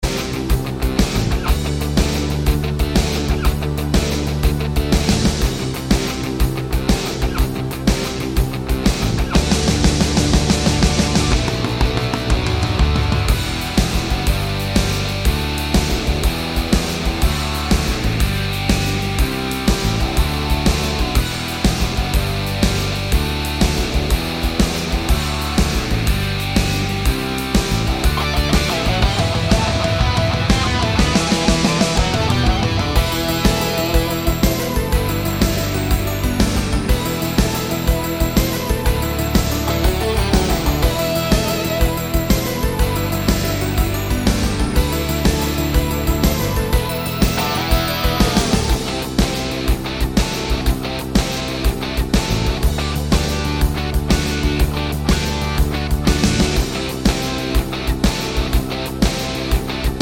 no Backing Vocals Rock 4:55 Buy £1.50